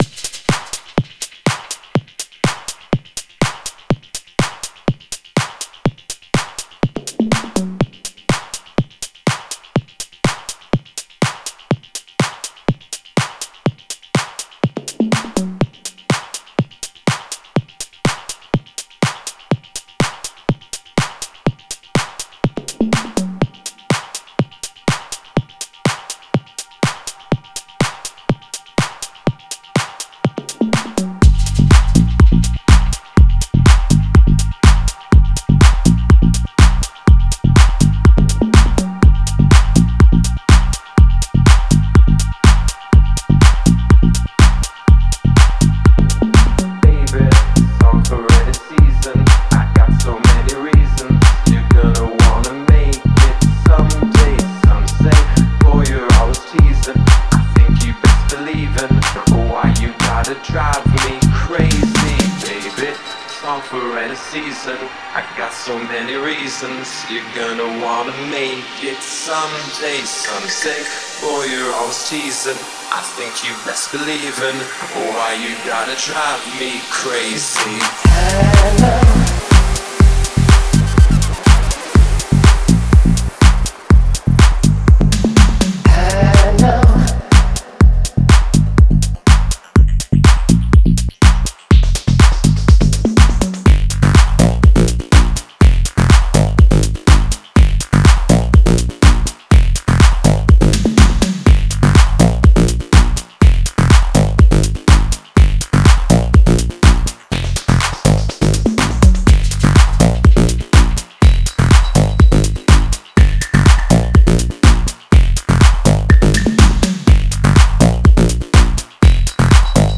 • Жанр: Pop, Dance, Electronic